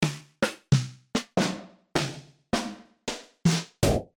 Digital drum machine based on low-fidelity samples, 24 dynamic pads and internal sequencer.
Resolution: 12 bits
all snare sounfs
snare2.mp3